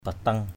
/ba-tʌŋ/ (cv.) pateng pt$ (d.) lưới (săn thỏ). wang bateng mâk tapay w/ bt$ mK tF%Y giăng lưới săn thỏ.